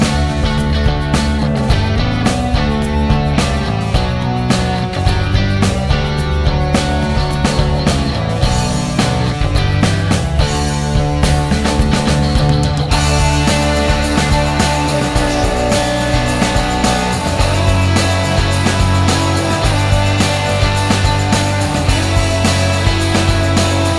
no Backing Vocals Indie / Alternative 5:07 Buy £1.50